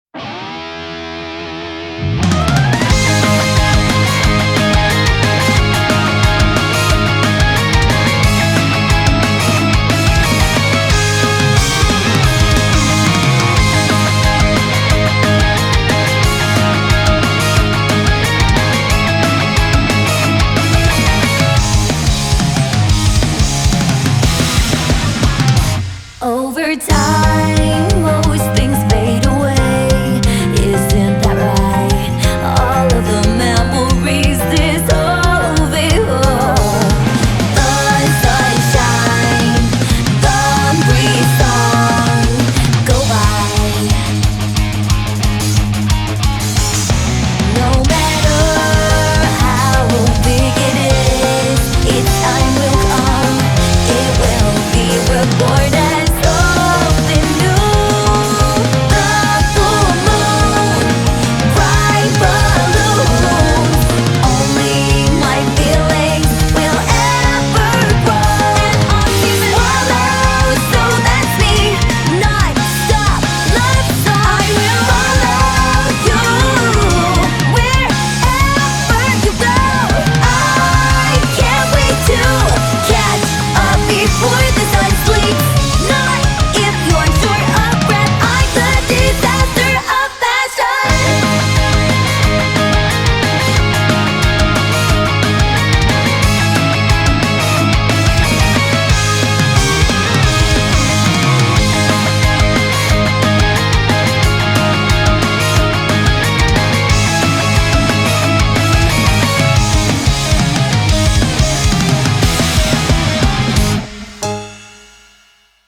BPM180
Audio QualityPerfect (High Quality)
Comentarios[UPLIFTING ROCK]